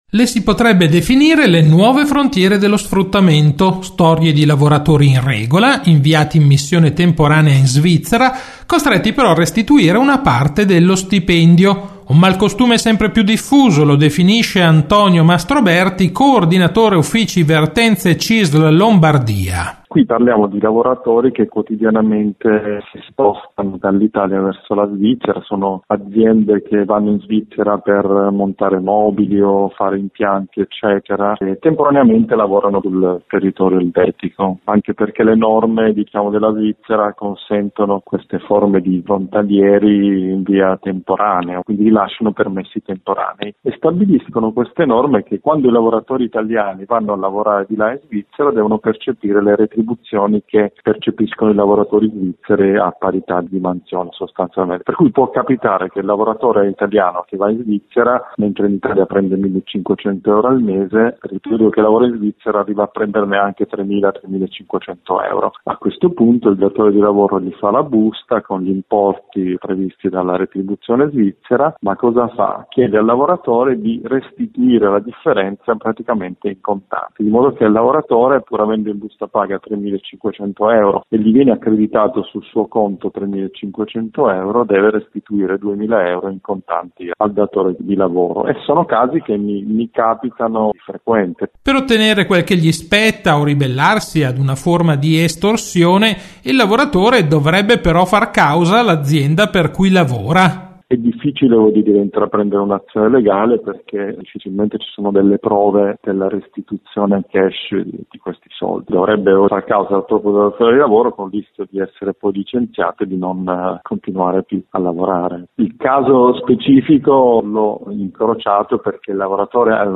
Di seguito la puntata del 26 febbraio di RadioLavoro, la rubrica d’informazione realizzata in collaborazione con l’ufficio stampa della Cisl Lombardia e in onda ogni quindici giorni su RadioMarconi il venerdì alle 12.20, in replica alle 18.10.